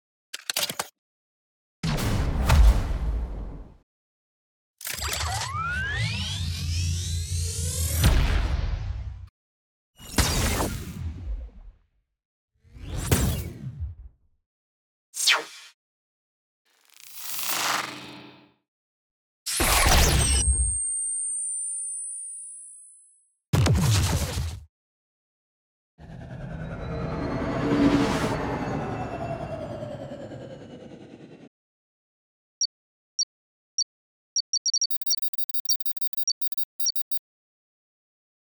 In this release you will find Hi-Tech sounds to design spaceships, futuristic weapons, forcefield and more.
All samples were recorded at 96kHz 24 bit with a Zoom H5 and Sennheiser ME 67.